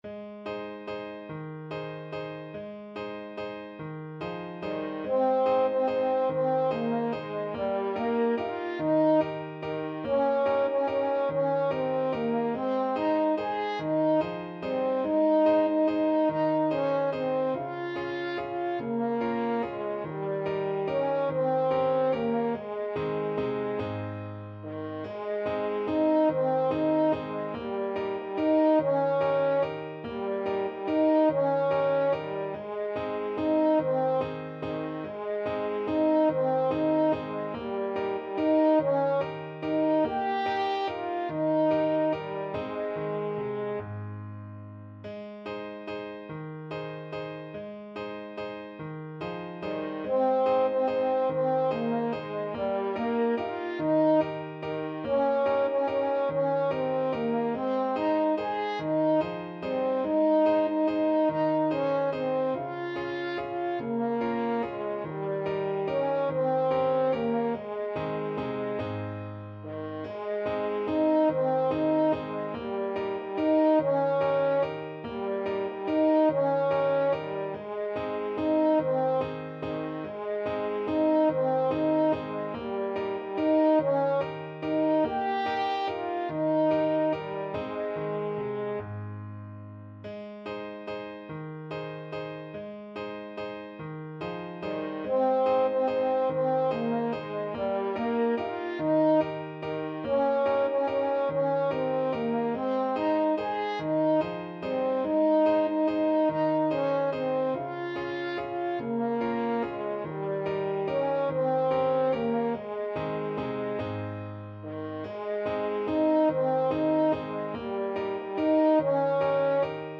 Steady one in a bar .=c.48
3/8 (View more 3/8 Music)
Traditional (View more Traditional French Horn Music)
Swiss